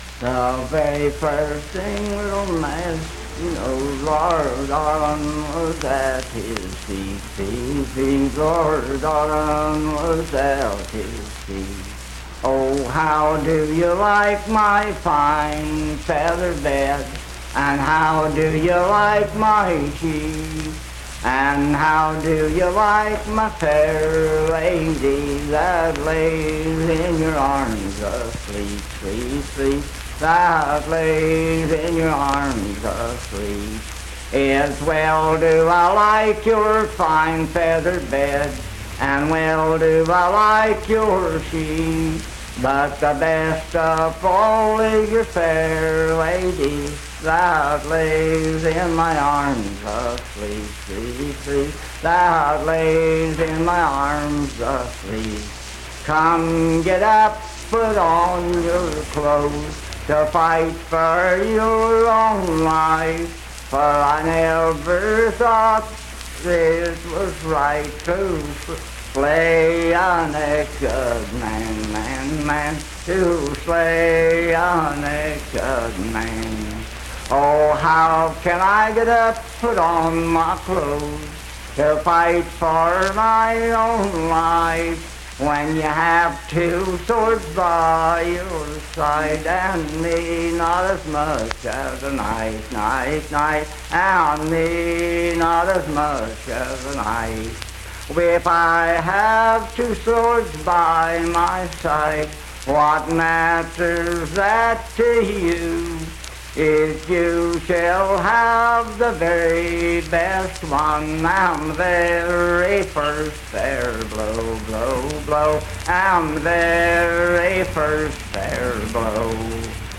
Unaccompanied vocal music performance
Voice (sung)
Spencer (W. Va.), Roane County (W. Va.)